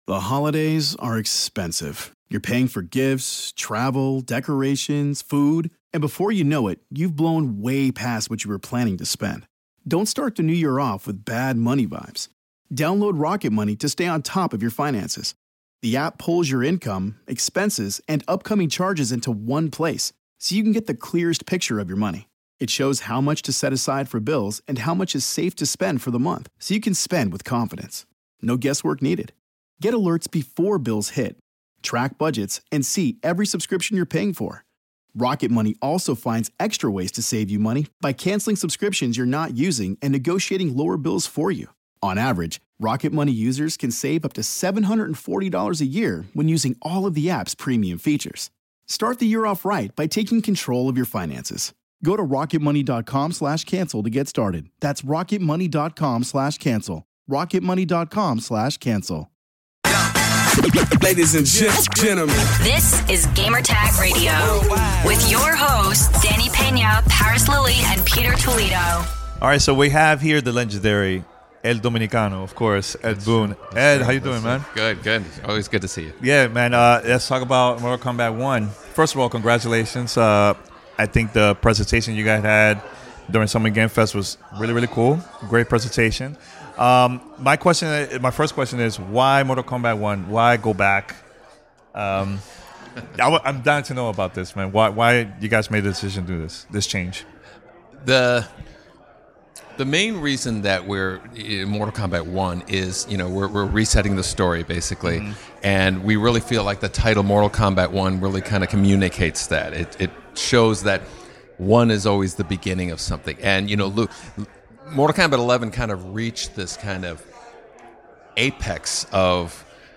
SGF23: Mortal Kombat 1 Interview with Ed Boon